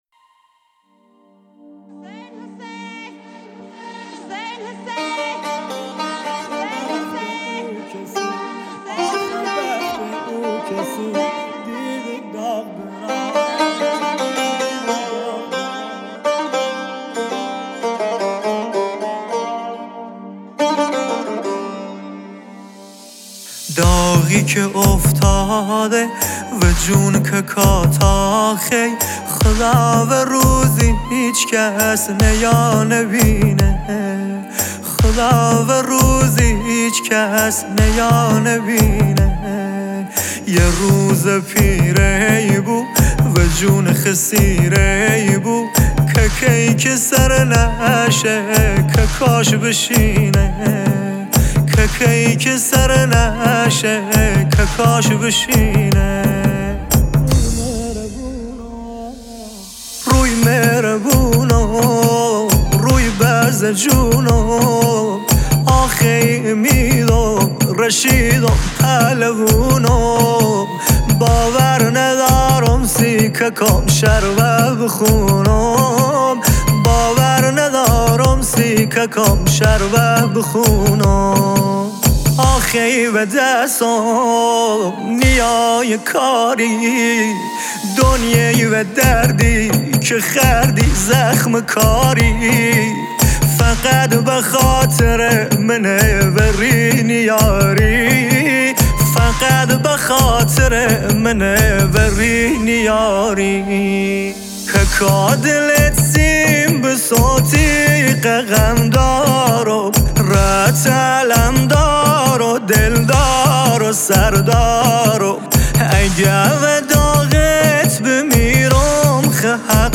هیئت قرآنی مذهبی راهیان کربلا مسجد امام جعفر صادق ( ع ) دهدشت
مداحی